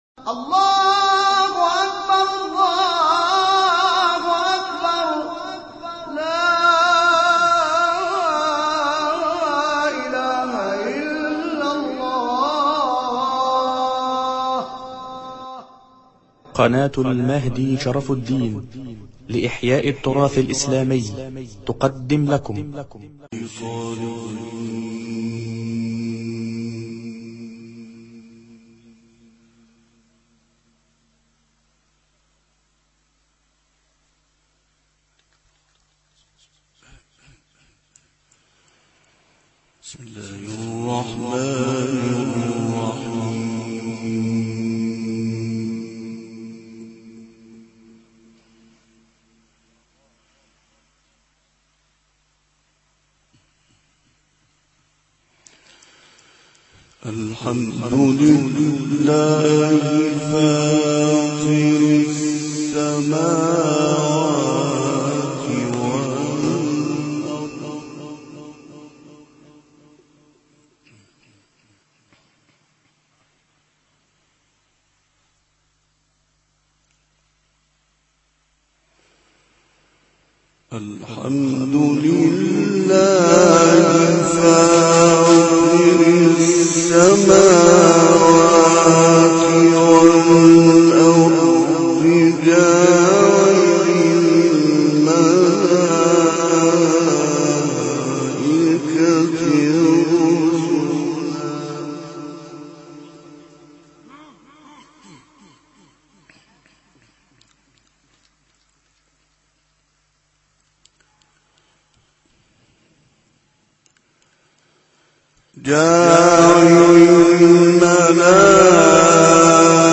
تلاوت «عبدالوهاب طنطاوی» از سوره فاطر
گروه شبکه اجتماعی: تلاوت آیات نورانی از سوره مبارکه فاطر، با صوت مرحوم عبدالوهاب طنطاوی ارائه می‌شود.
این تلاوت 46 دقیقه‌ای، در کشور مصر اجرا شده است.